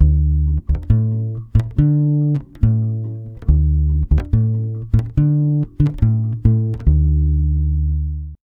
140BASS D7 3.wav